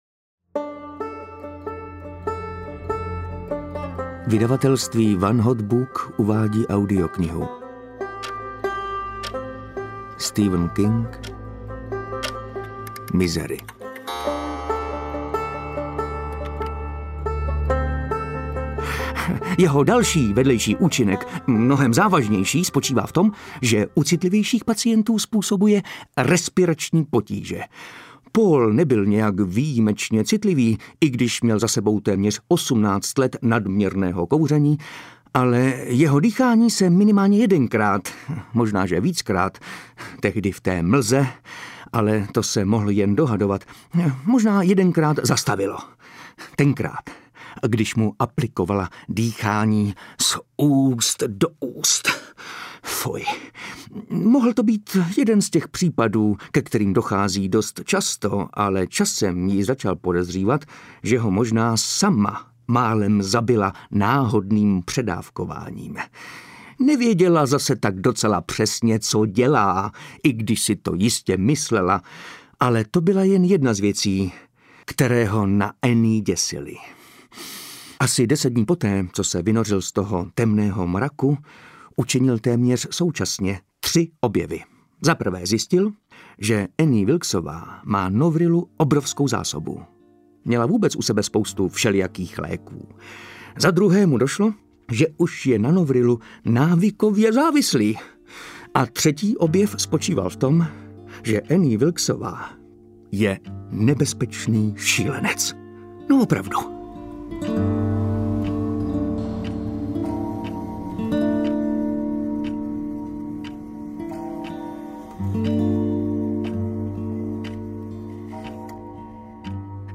Misery audiokniha
Ukázka z knihy
• InterpretMartin Myšička